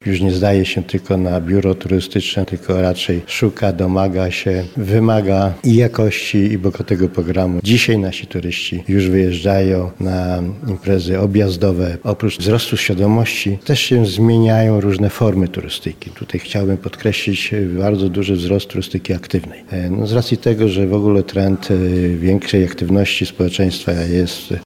Aktywna i objazdowa turystyka to najnowsze trendy w podróżowaniu, o których mówiono podczas ogólnopolskiej konferencji studencko-doktoranckiej na Uniwersytecie Marii Curie-Skłodowskiej.
Dziś zakończenie Studenckiej Wiosny Teatralnej Konferencja odbyła się w Wydziale Nauk o Ziemi i Gospodarki Przestrzennej UMCS w Lublinie.